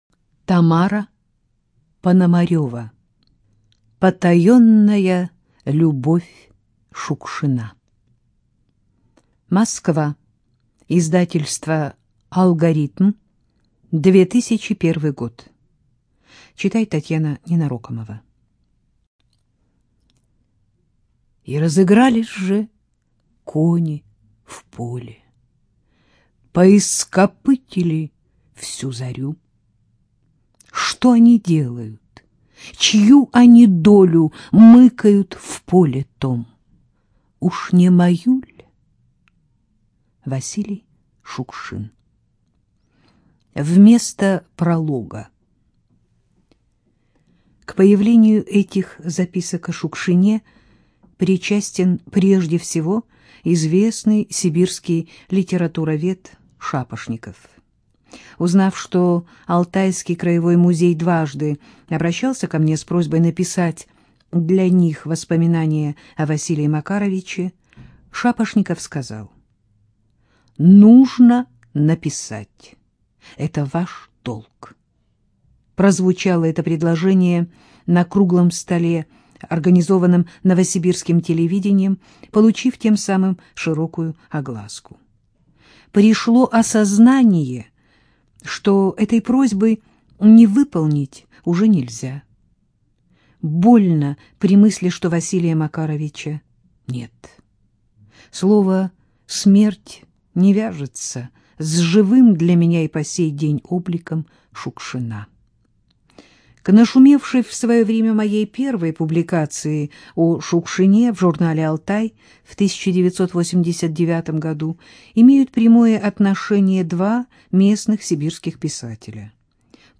Студия звукозаписизвукотэкс